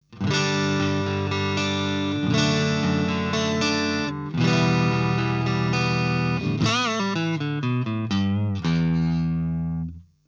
Elektro gitar deneyleri kapsamında, farklı kalınlıklardaki penaların ses üzerindeki etkisi de karşılaştırılmıştır.
Karşılaştırmalar, Fender Stratocaster (Meksika üretimi – 3 adet Single Coil manyetikli) gitar ile gerçekleştirilmiştir. Gitar, Marshall JVM 410H amfisi üzerinden, özel hoparlör konfigürasyonuna sahip MR1960B 4x12 kabin ile çalınmıştır. Ses örnekleri, Shure SM57 mikrofon kullanılarak kaydedilmiştir.
Ancak sadece sap manyetiği aktif bırakılmıştır.